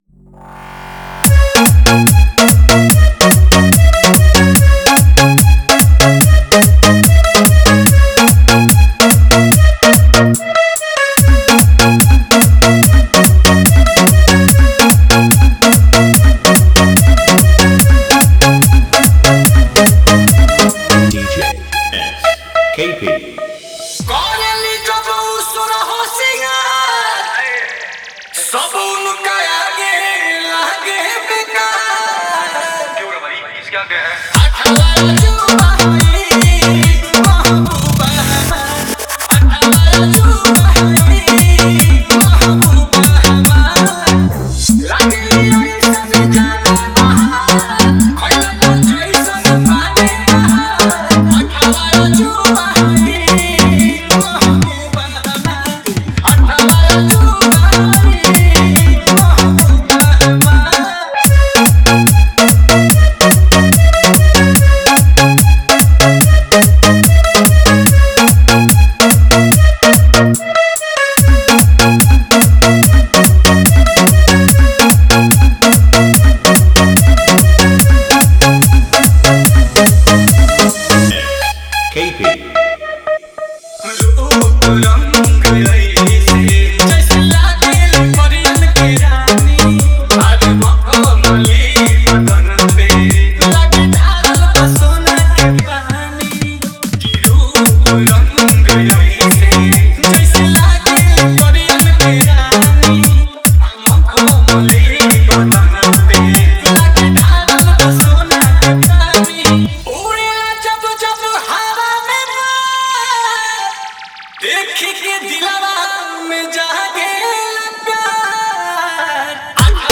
Category : Haryanvi Dj Remix Jhanjhan Bass